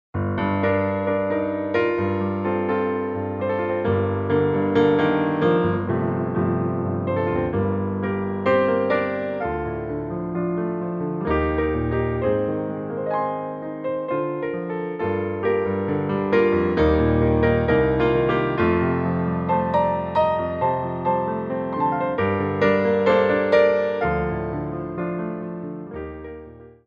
Adage
4/4 (8x8)